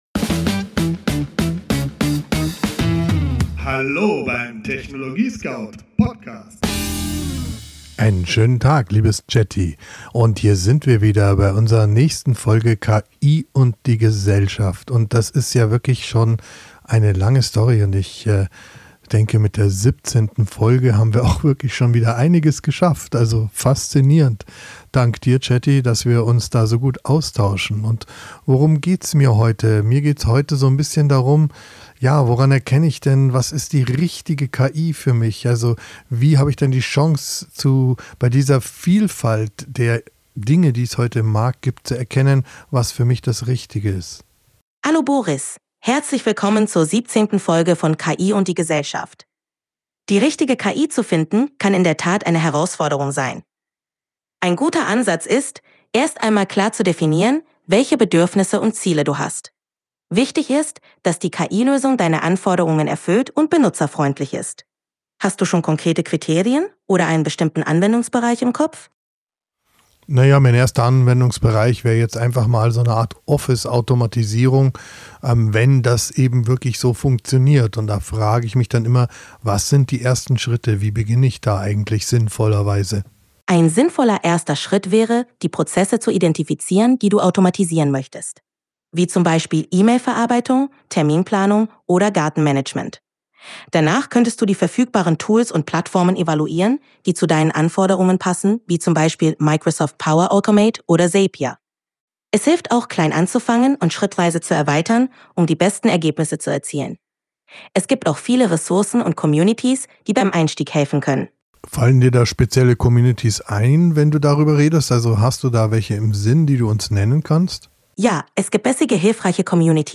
Dialog im TechnologieScout-Studio